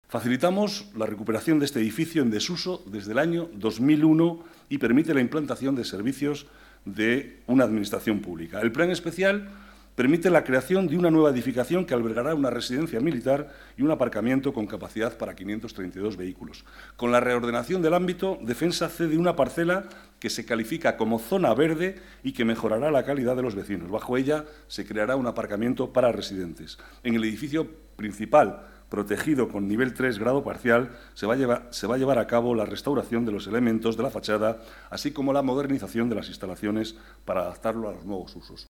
Nueva ventana:Declaraciones del vicealcalde, Manuel Cobo, sobre el cambio de uso del hospital militar del Generalisimo